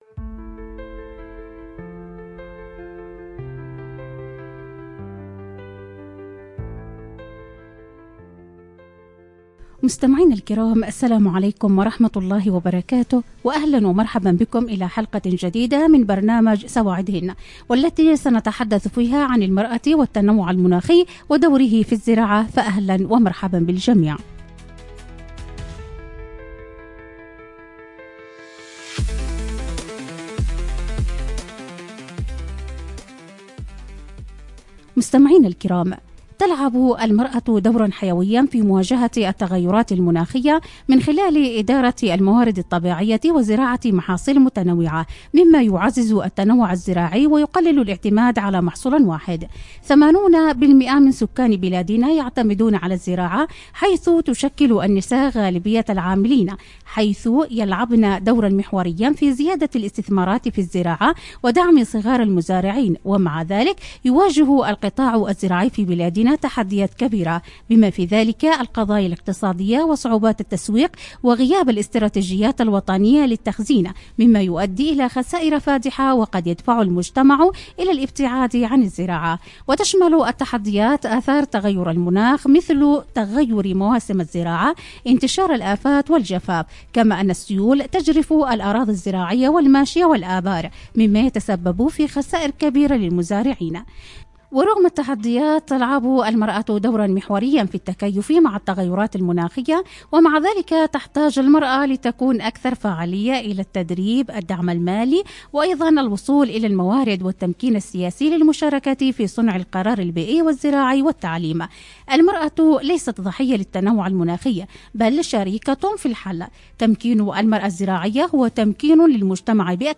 📻 المكان: عبر أثير إذاعة رمز